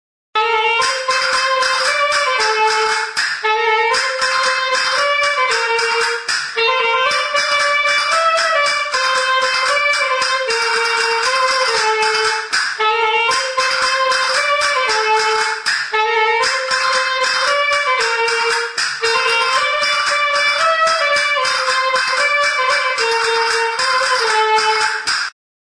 Instrumentos de músicaHUESICOS
Idiófonos -> Frotados / friccionados
Hezurrak harraskatzeko kastaineta beltz bat du.